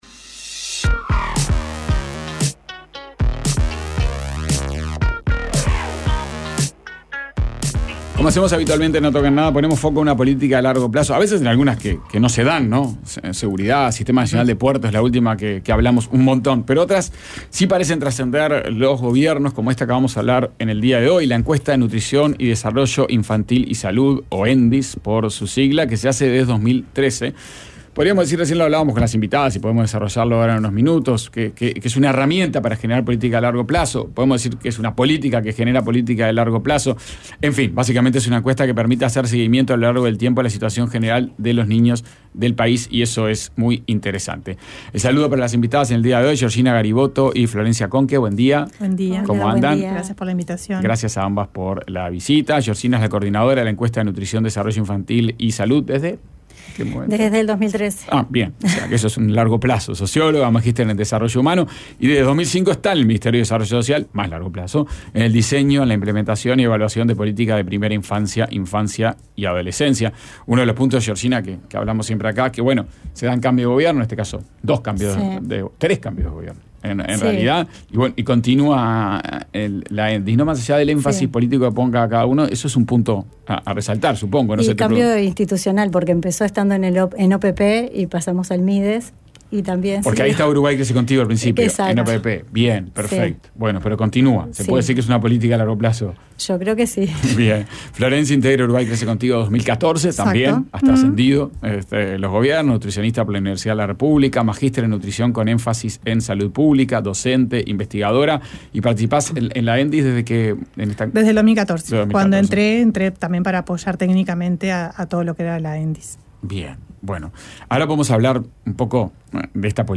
Entrevista a Carmen Sánchez, presidenta de la Agencia Nacional de Desarrollo (ANDE).